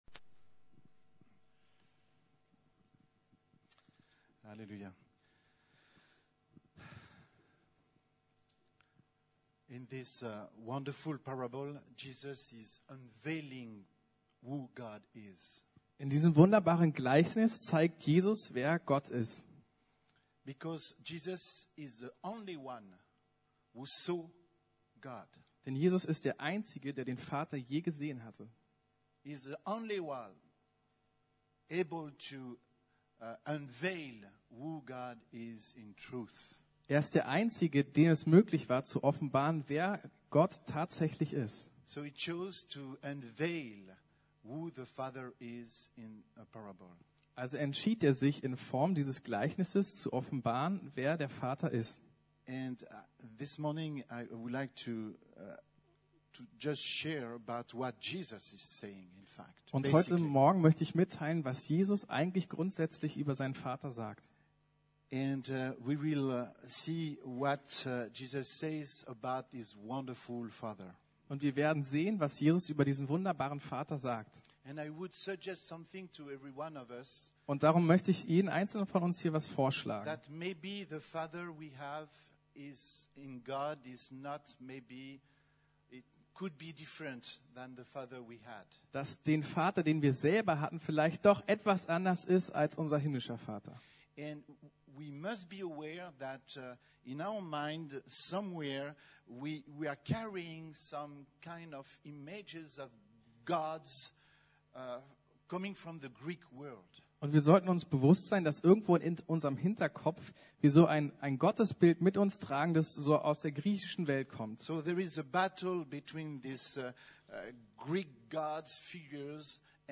Das Vaterherz Gottes ~ Predigten der LUKAS GEMEINDE Podcast